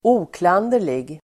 Uttal: [²'o:klan:der_lig]